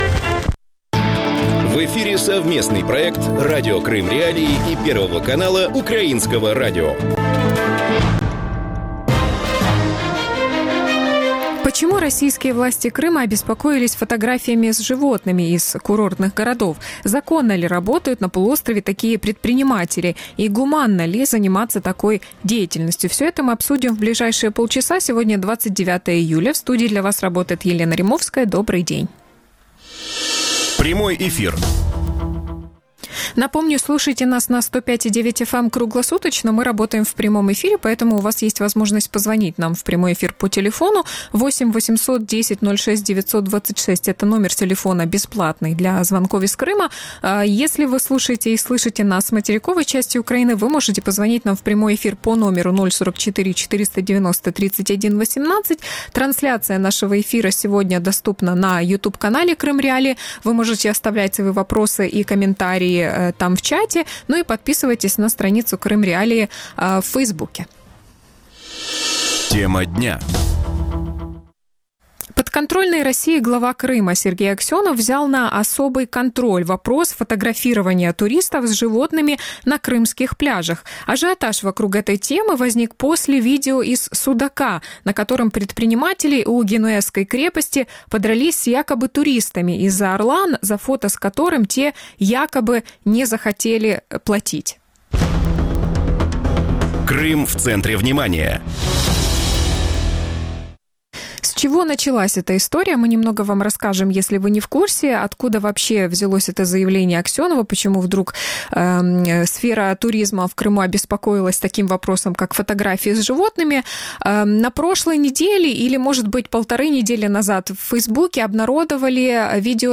Гости эфира